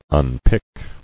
[un·pick]